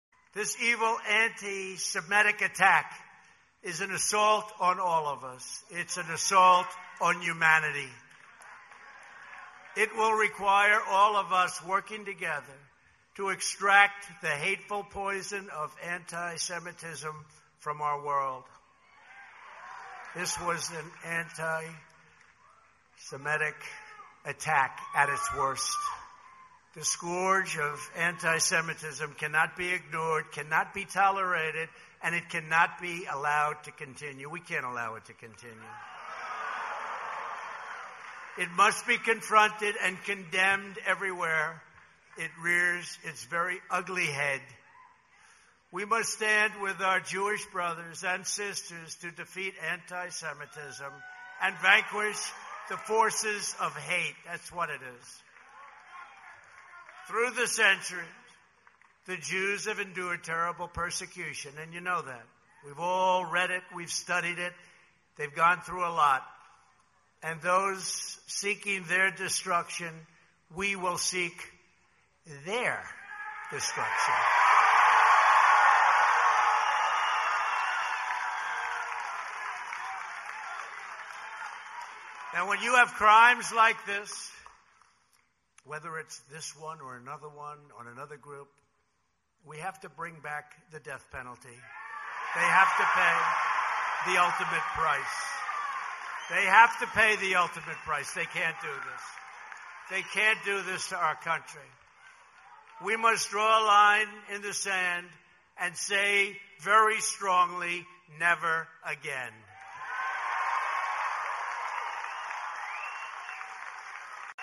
➡ This is a short video released by the Settingbrushfires channel that has no context other than the speech recently given by former US President Trump regarding the execution of antisemites. This is coming off the back of the campus protests for Gaza that just swept the nation, and many patriots are wondering about the validity of the news coverage of these events, however as you can see in this video, Trump does not have that concern.